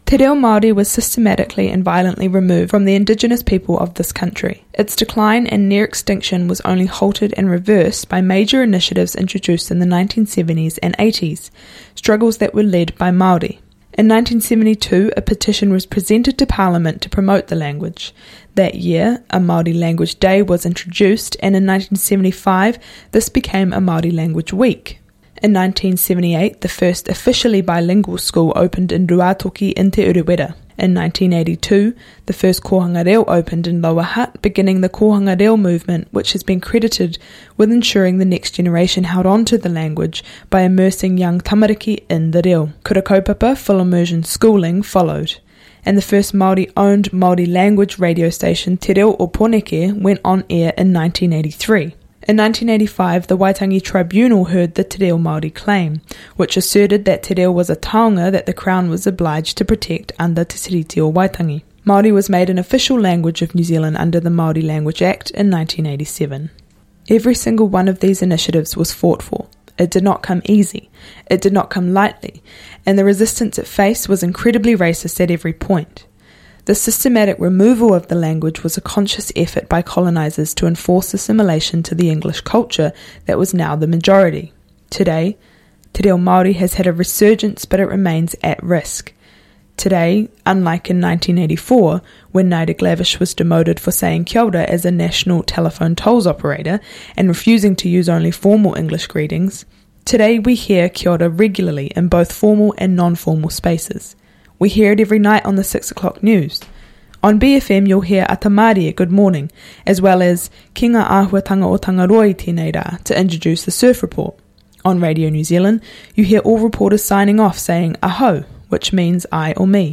I/V w/ Guyon Espiner on Te Reo Māori [full version]: December 12, 2018